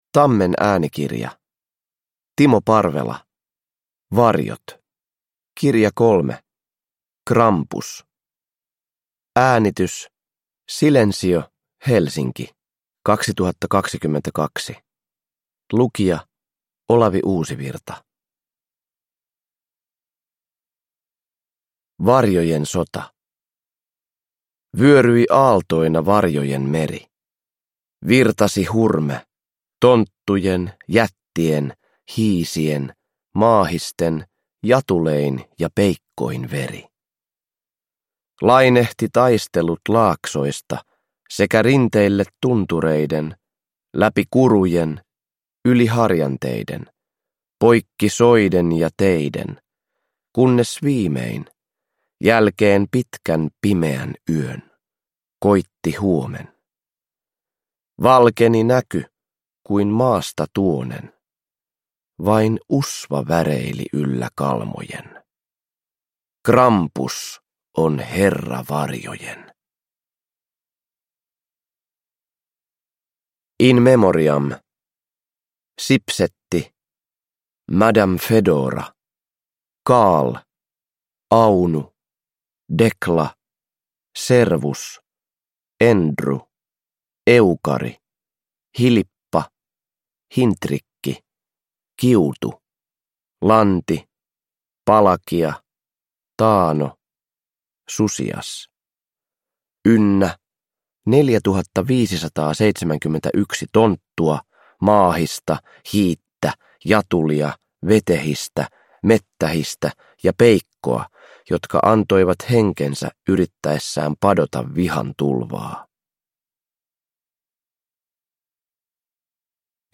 Uppläsare: Olavi Uusivirta